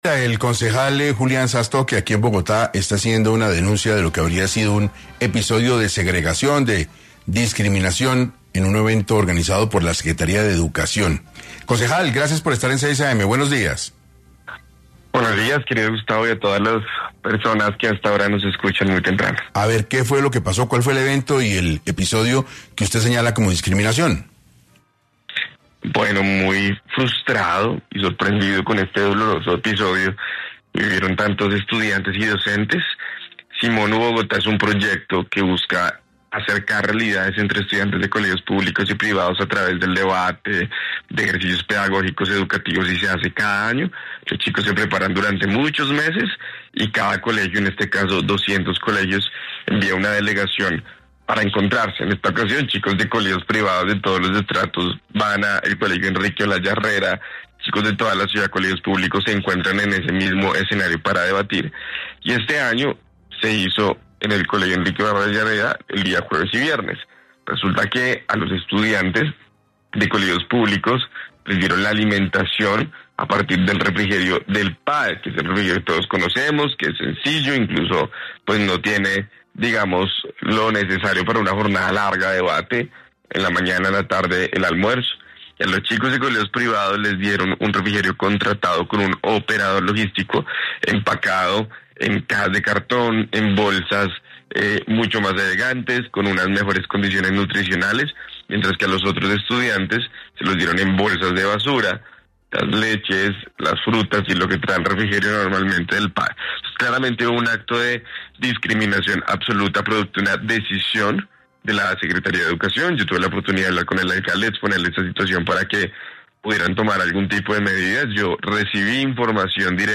Julián Sastoque, concejal de Bogotá, habló en 6AM sobre un posible caso de segregación en un evento que reunía estudiantes de colegios privados y públicos.
En entrevista con 6AM de Caracol Radio, Sastoque relató que, durante las jornadas realizadas el pasado jueves y viernes, se presentaron diferencias en la entrega de refrigerios: